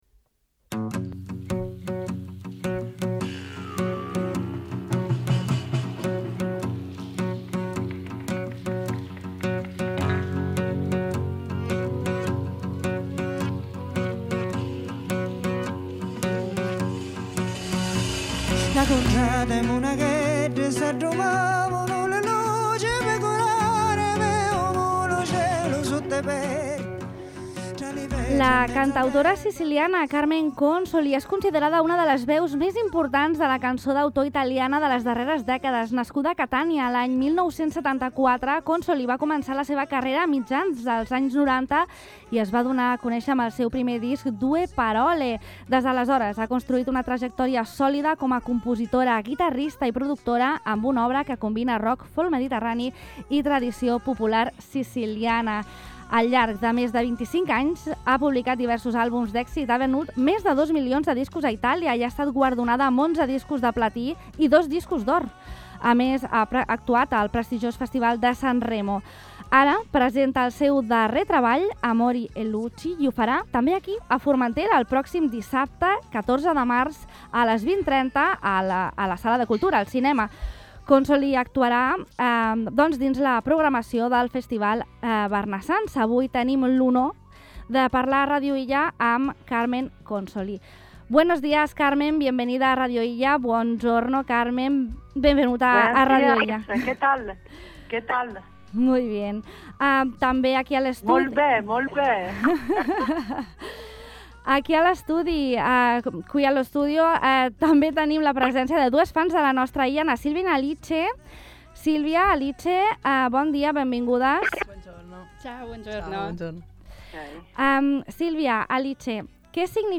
Durant l’entrevista, Consoli ha explicat que ‘Amuri luci’ és un projecte profundament vinculat a la seva terra, Sicília, i a la identitat mediterrània.